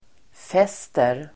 Uttal: [f'es:ter]